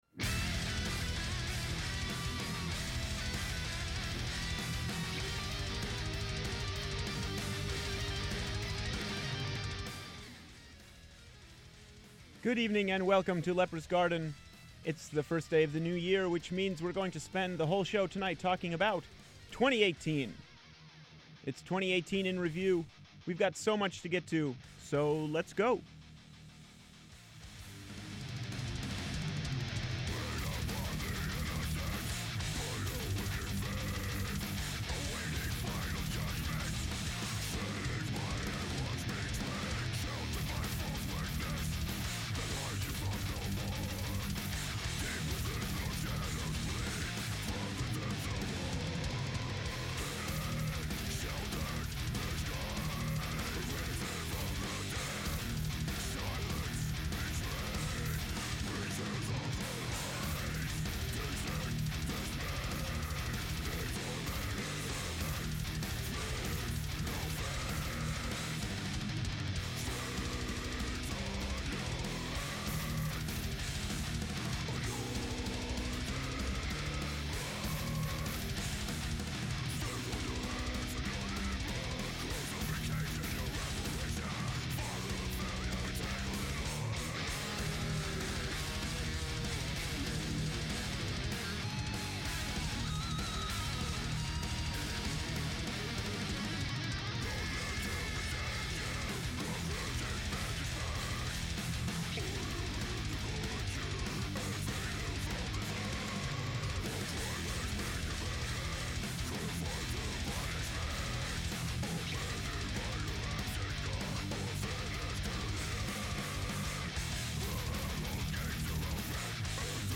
Recorded in the studios of WMPG-FM in Portland, Maine.